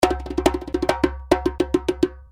Djembe loops - 105 BPM (22 variatioans)
Real djembe loops playing at 105 bpm.
The Djembe loops are already in mix mode, with light compression and EQ.
The Djembe was recorded using vintage neumann u87 as main microphone, And 2 451 AKG microphones for the stereo ambient sound. The loops are dry with no effect , giving you freedom, adding the right effect to your project. Djembe is west african drum but the loops here are more ethnic, arabic and brazilian style .